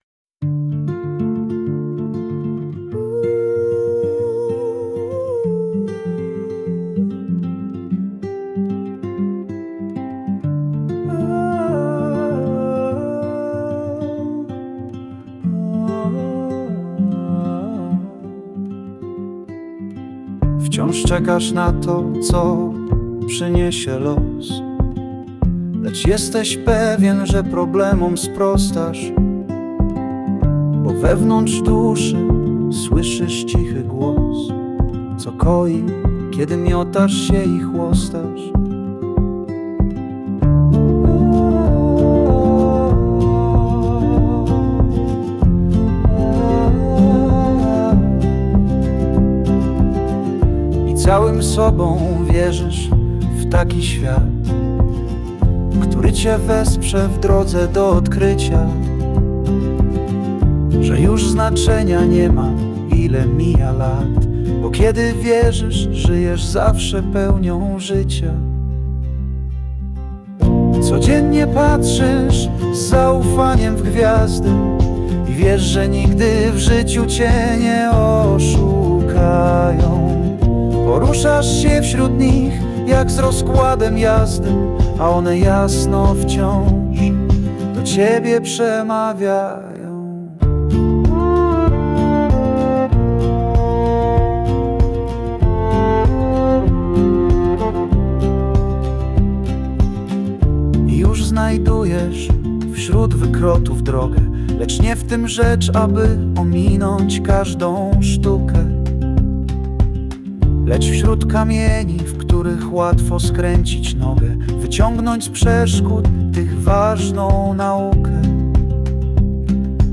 Powyższy utwór przerobiony przez AI na piosenkę.